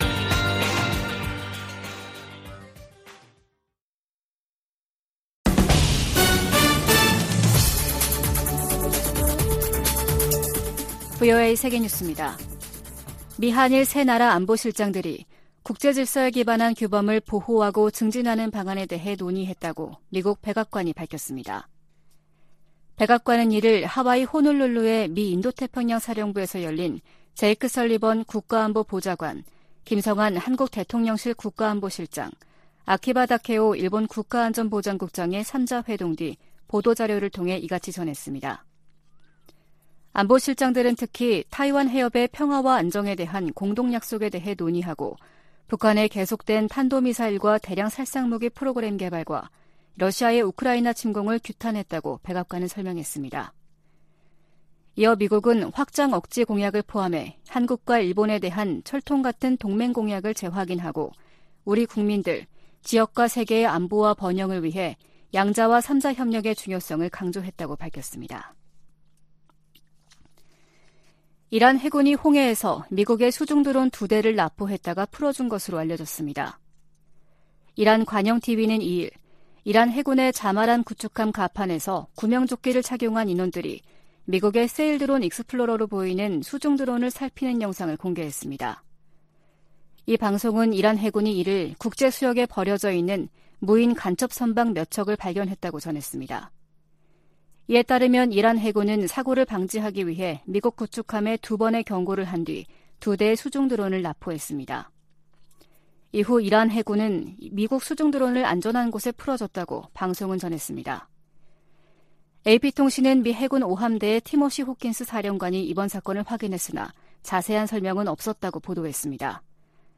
VOA 한국어 아침 뉴스 프로그램 '워싱턴 뉴스 광장' 2022년 8월 24일 방송입니다. 미국과 한국, 일본 안보실장들이 하와이에서 만나 북한 미사일 프로그램을 규탄하고 타이완해협 문제 등을 논의했습니다. 미국과 한국 간 경제 협력을 강화하기 위한 미 의원들의 움직임이 활발해지고 있습니다. 미국 검찰이 북한의 사이버 범죄 자금에 대한 공식 몰수 판결을 요청하는 문건을 제출했습니다.